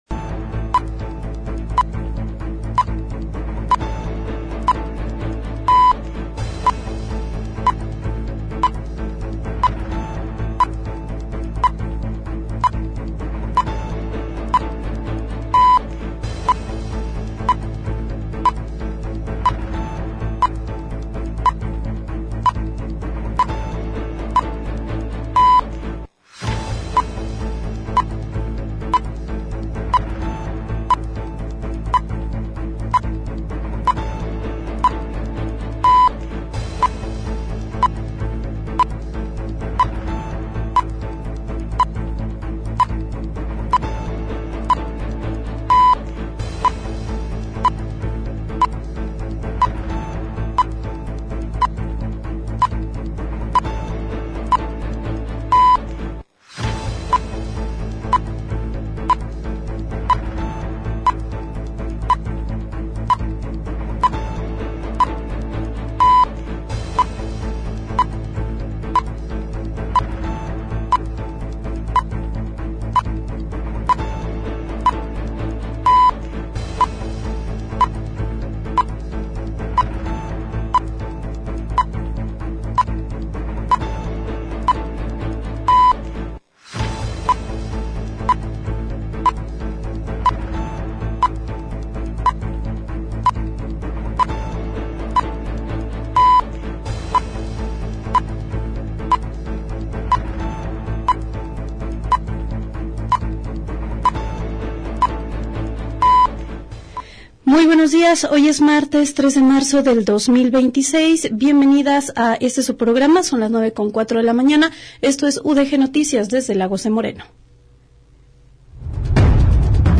Espacio periodístico dedicado a recopilar, analizar y difundir los acontecimientos más relevantes de una comunidad específica. Ofrece cobertura puntual de los hechos más importantes a nivel local y regional.